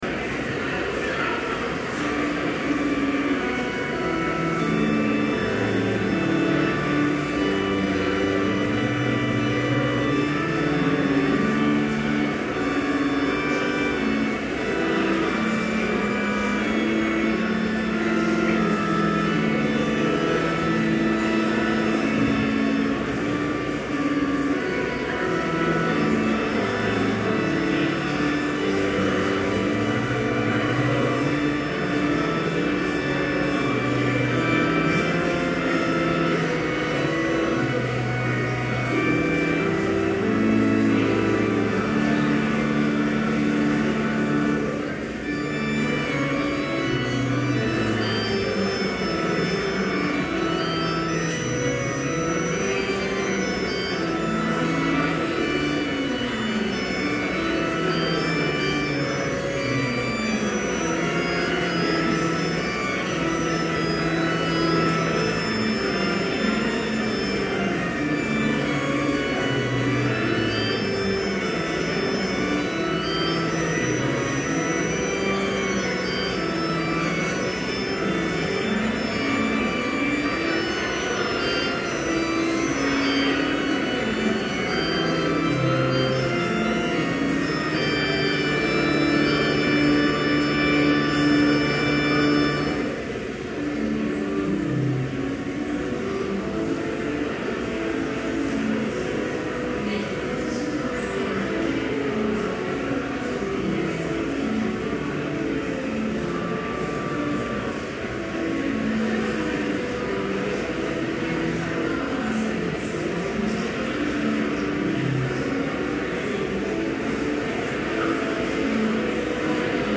Chapel service held on September 26, 2011, in Trinity Chapel (audio available)
Complete service audio for Chapel - September 26, 2011